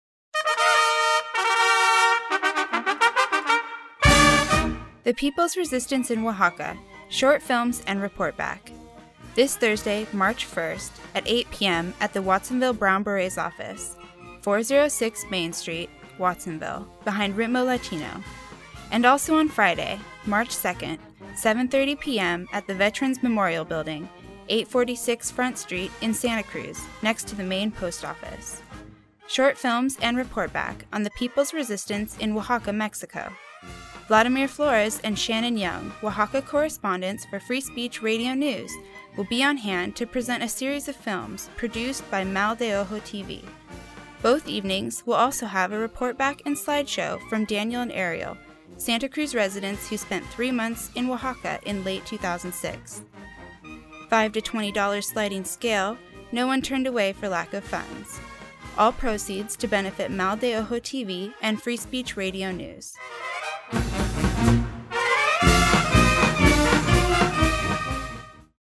PSA: The People's Resistance in Oaxaca - March 1 & 2